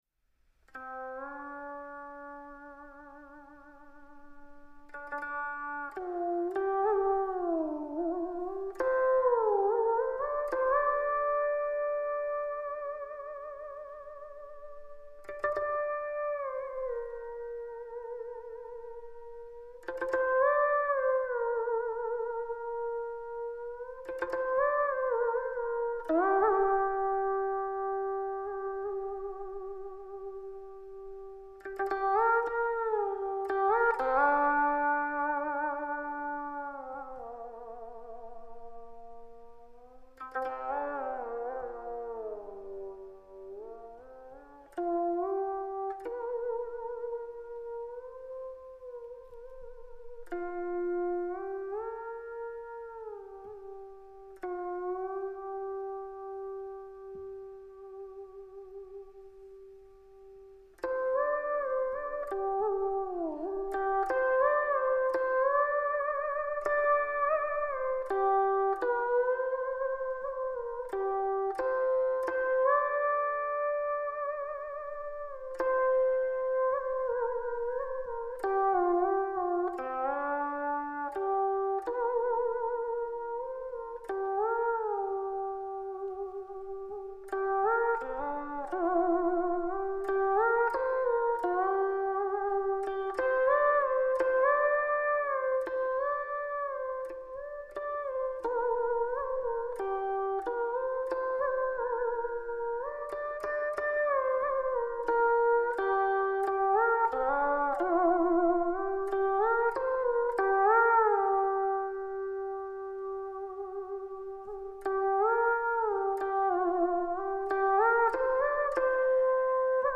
1580首珍贵民族音乐